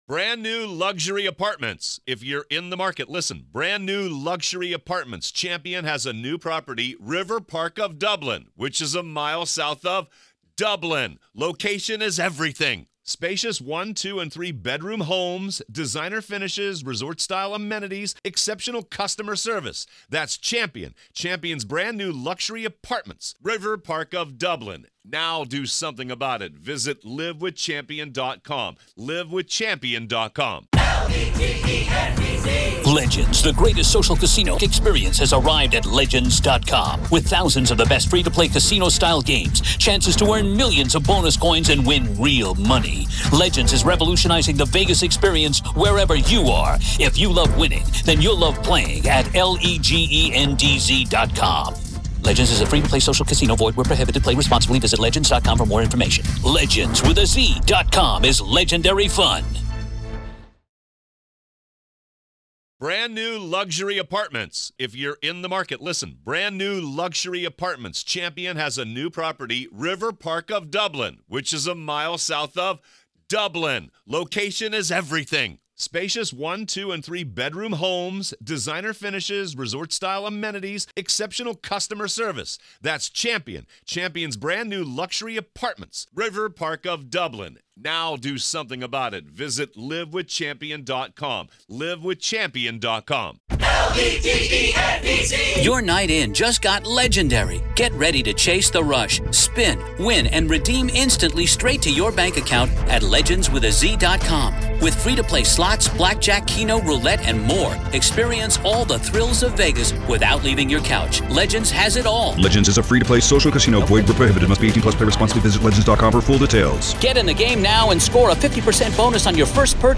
This gripping podcast series transports you to the heart of the legal proceedings, providing exclusive access to the in-court audio as the prosecution and defense lay out their arguments, witnesses testify, and emotions run high.